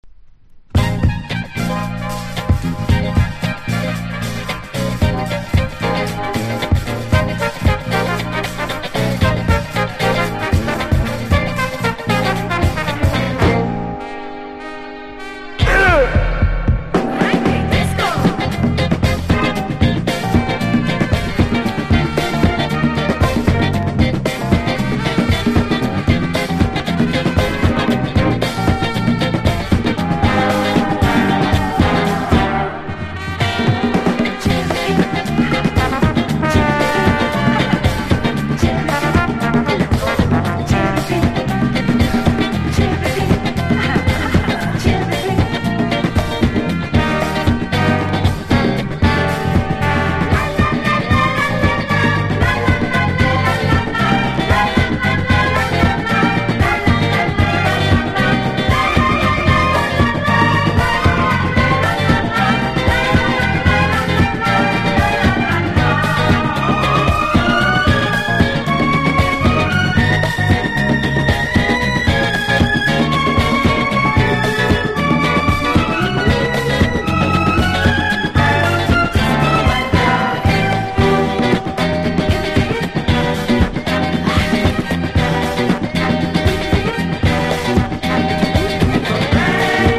軽快な